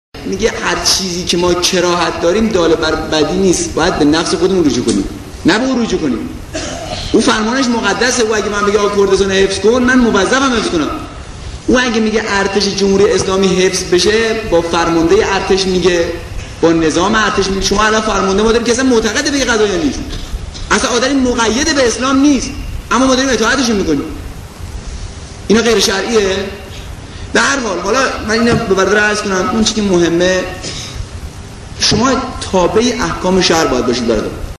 برشی از سخنرانی شهید محمد بروجردی در مورد کردستان